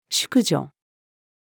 淑女-female.mp3